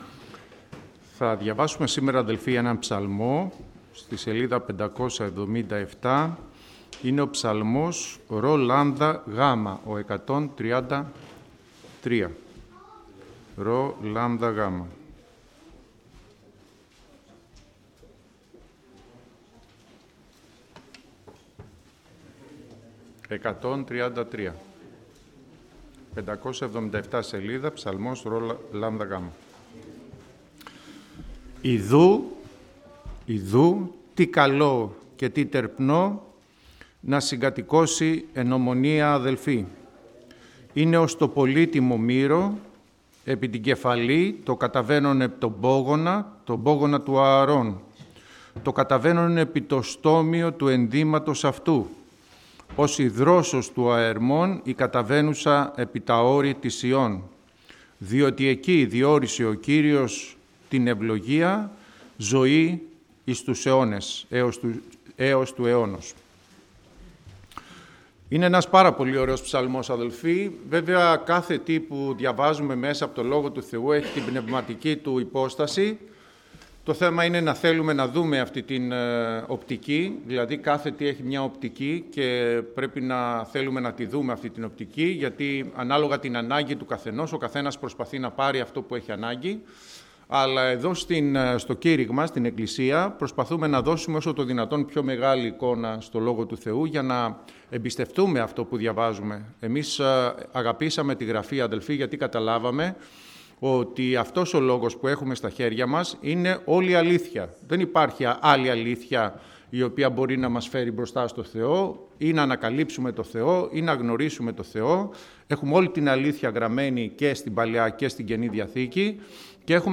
Κυριακάτικο Κήρυγμα – Ελευθέρα Αποστολική Εκκλησία Πεντηκοστής Βούλας